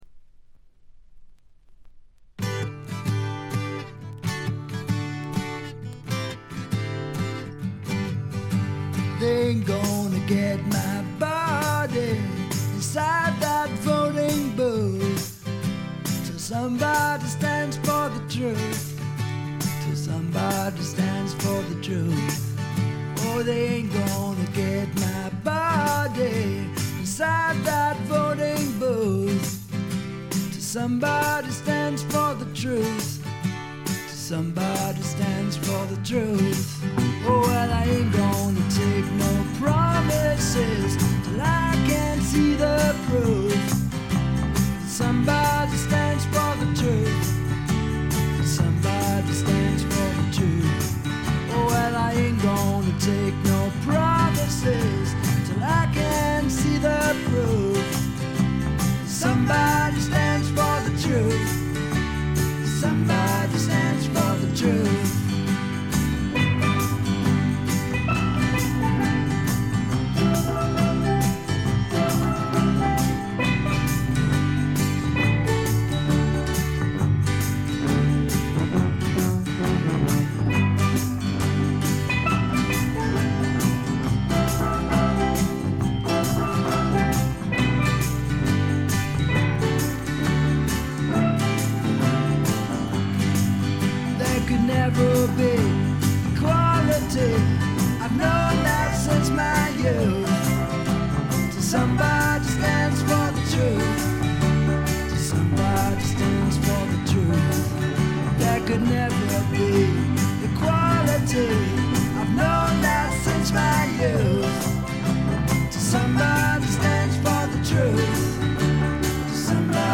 ホーム > レコード：英国 SSW / フォークロック
試聴曲は現品からの取り込み音源です。